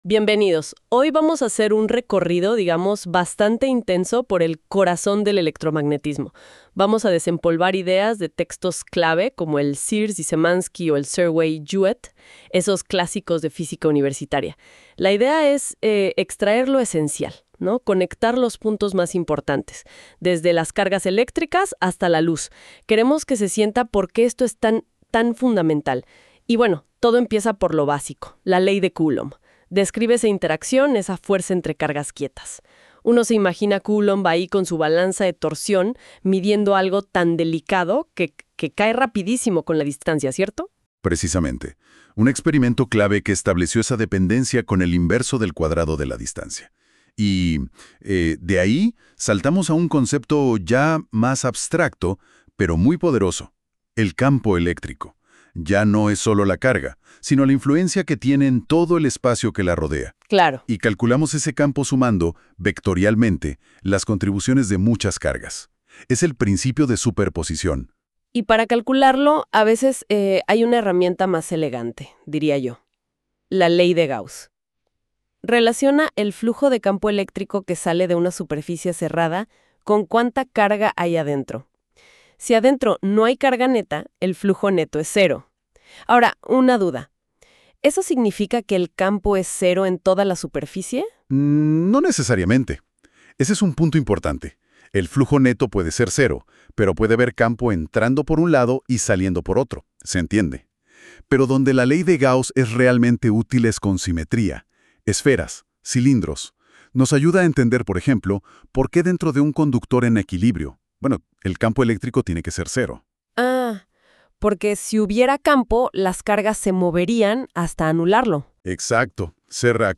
El presente material ha sido generado mediante NotebookLM, una herramienta de inteligencia artificial desarrollada por Google.